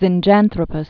(zĭn-jănthrə-pəs, zĭnjăn-thrōpəs)